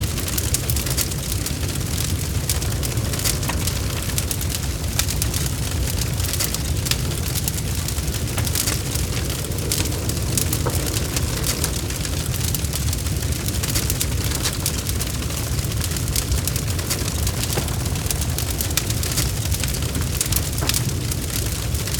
На этой странице собраны звуки горящей машины — треск пламени, взрывы, деформация металла.
Звуки горящего автомобиля: Шум горящей машины